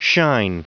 Prononciation du mot shine en anglais (fichier audio)
Prononciation du mot : shine